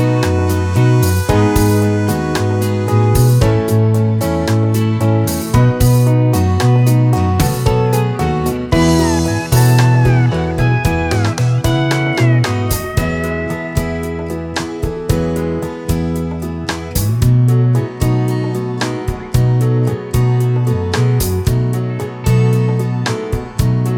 no Backing Vocals Country (Male) 3:24 Buy £1.50